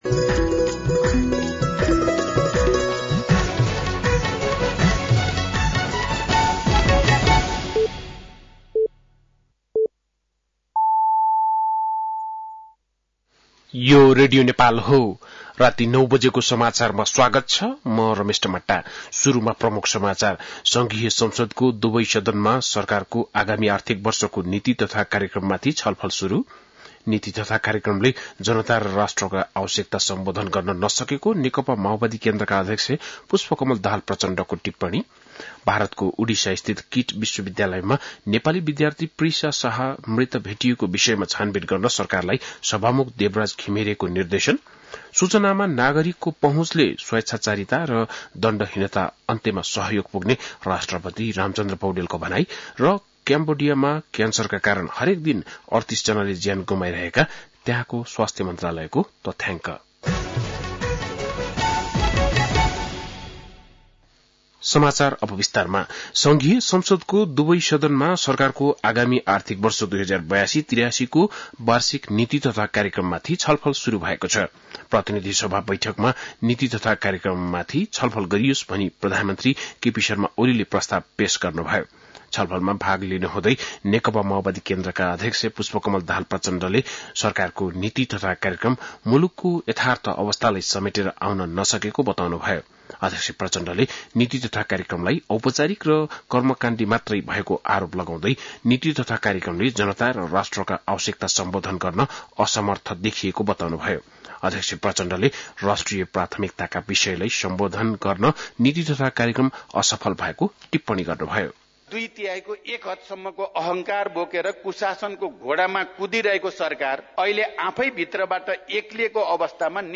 बेलुकी ९ बजेको नेपाली समाचार : २२ वैशाख , २०८२
9-PM-Nepali-NEWS-01-22.mp3